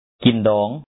kǐn-dɔ̌ɔŋ to marry